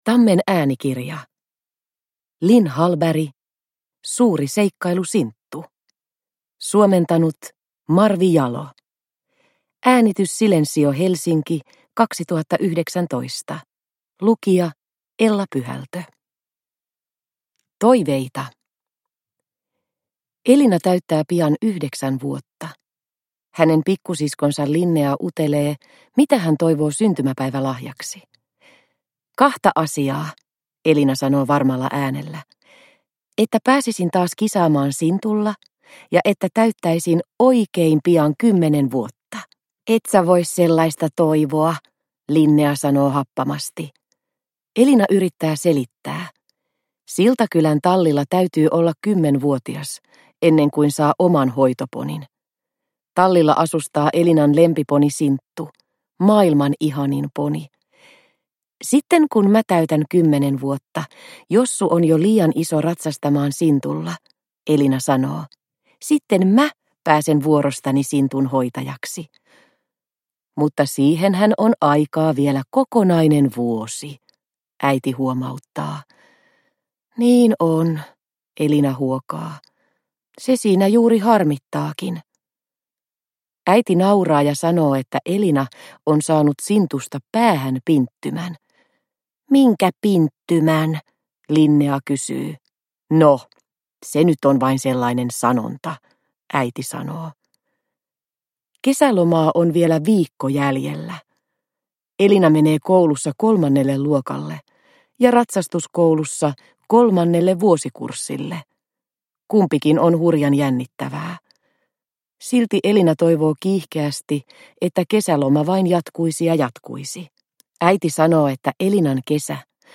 Suuri seikkailu, Sinttu – Ljudbok – Laddas ner